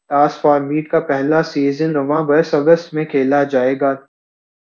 deepfake_detection_dataset_urdu / Spoofed_TTS /Speaker_13 /13.wav